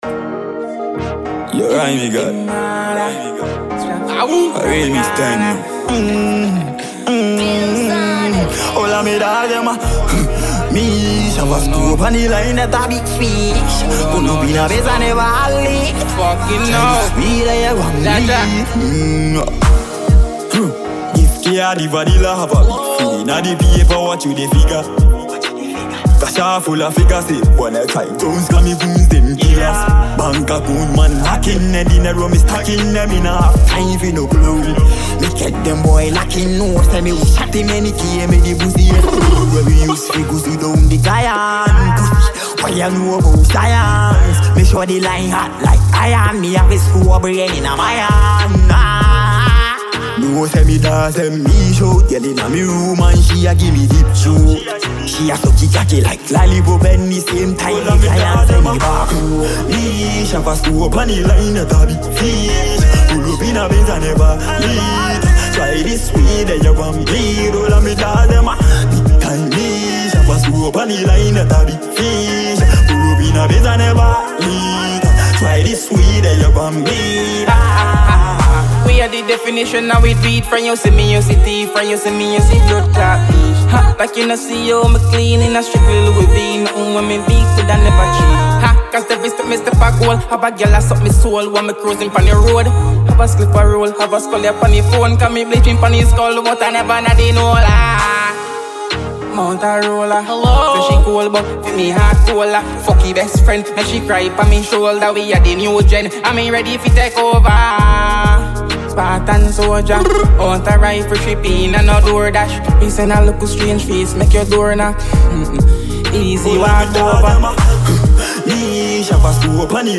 a super-talented Ghanaian dancehall artist.